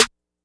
(SXJ) Snare (6).wav